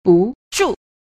3. 不住 – bù zhù – bất trụ (không trụ vững)
bu_zhu.mp3